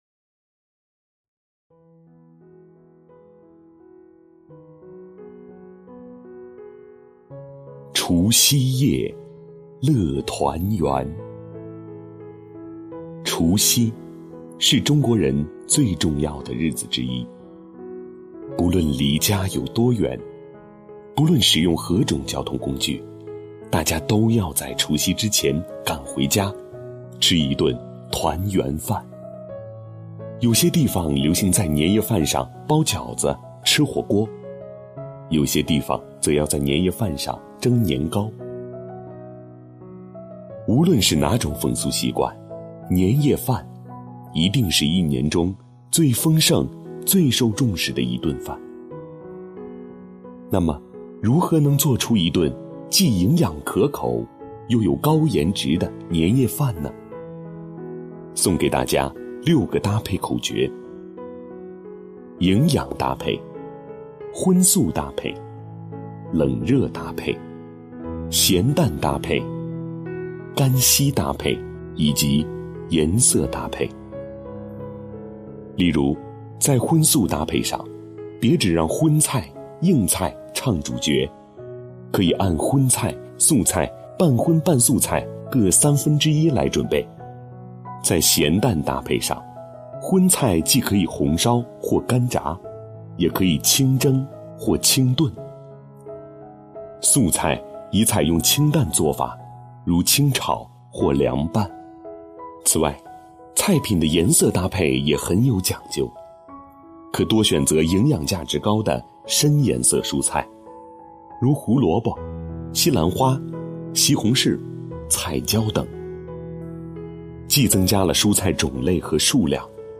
主播